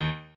piano7_17.ogg